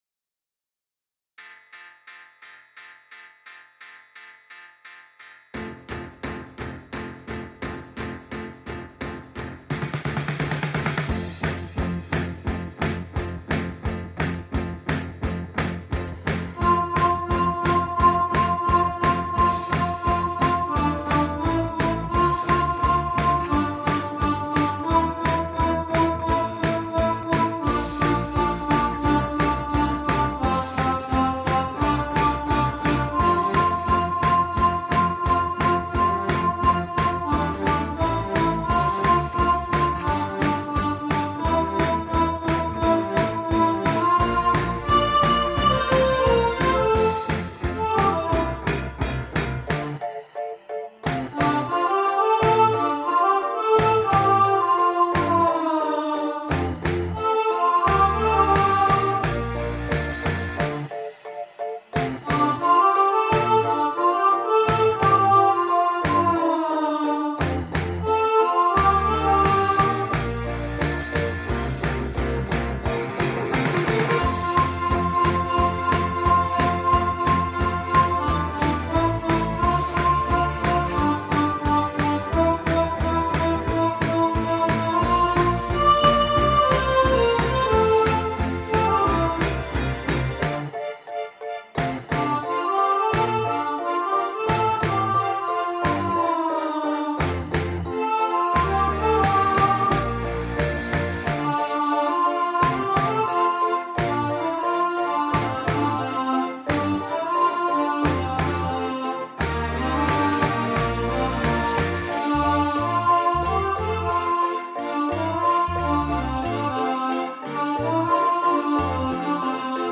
Mr Blue Sky – Alto | Ipswich Hospital Community Choir